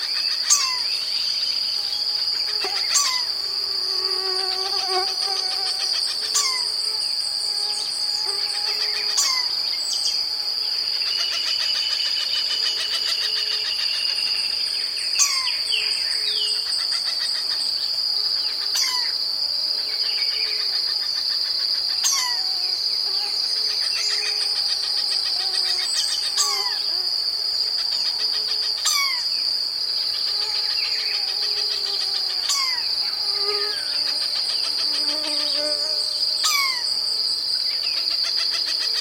Cream-backed Woodpecker (Campephilus leucopogon)
Vocalización poco usual, de una pareja.
Sex: Both
Detailed location: Reserva Paranacito
Condition: Wild
Certainty: Observed, Recorded vocal